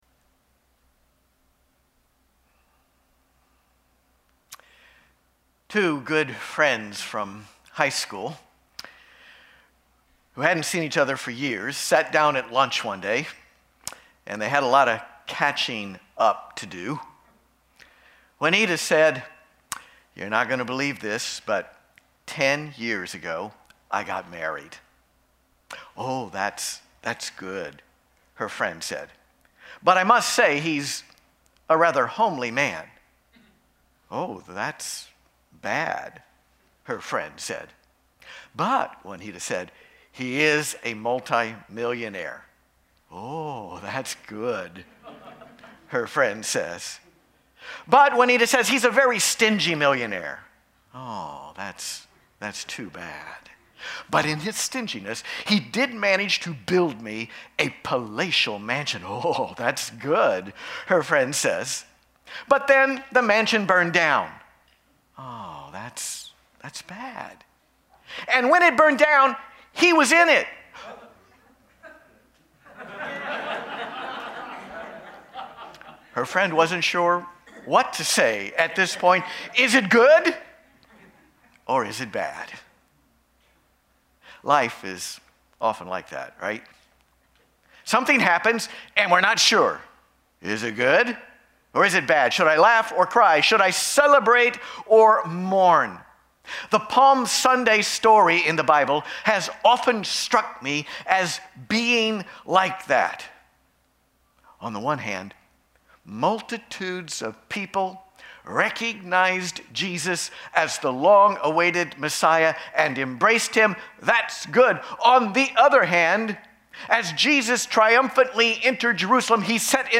LIVE Stream Replay